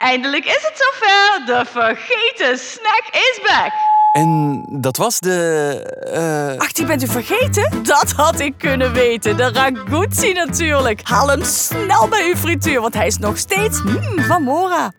De Vergeten Snack op de radio!
3 verschillende radiospots, waarin de enige echte Cora van Mora, de luisteraars zal verleiden om de Mora Vergeten Snacks te ontdekken bij hun lokale frituur,  zullen te horen zijn op onder andere deze radiostations: QMusic, JOE, MNM, Studio Brussel,… in Vlaanderen.